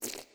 SFX_Slime_Defeated_01.wav